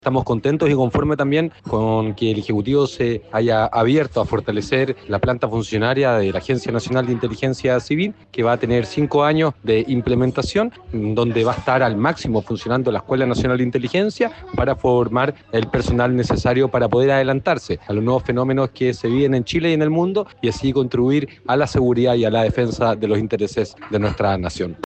El diputado del Frente Amplio, Jorge Brito, sostuvo que la reforma busca contribuir a la especialización de funcionarios y fortalecer los instrumentos de planificación.